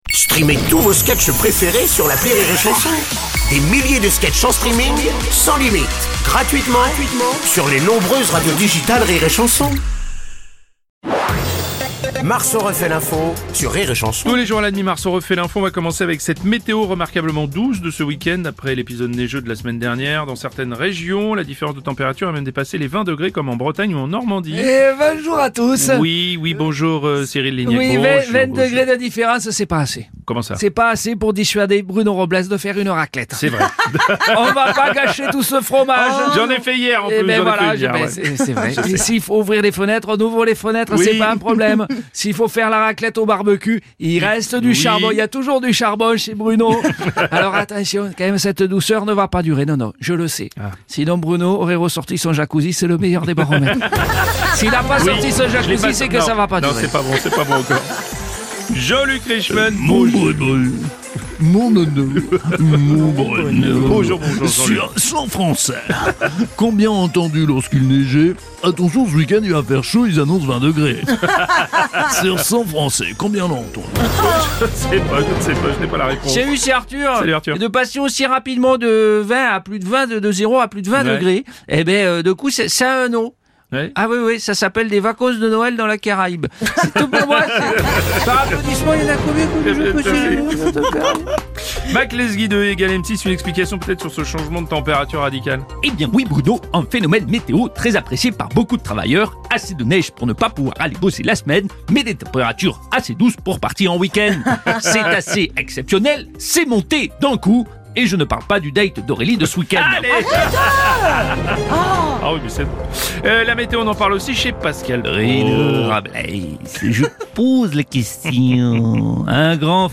Comédie pour toute la famille Divertissement Rire et Chansons France Chansons France Tchat de Comédiens Comédie